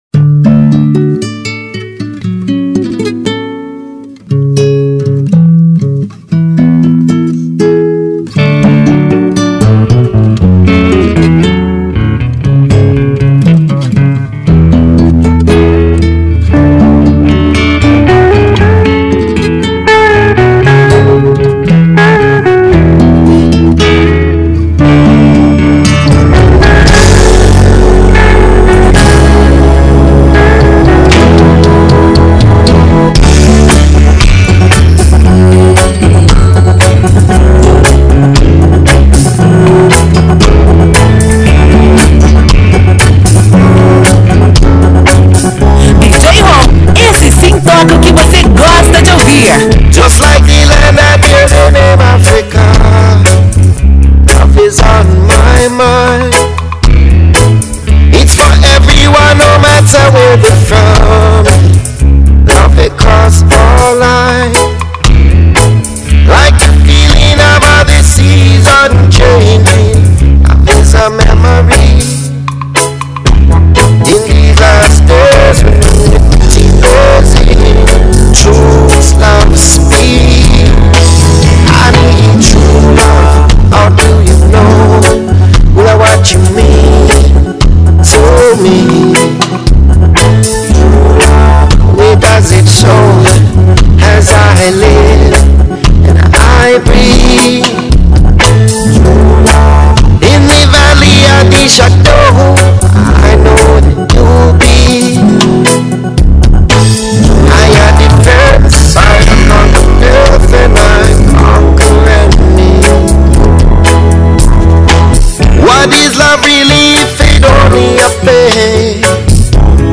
funk.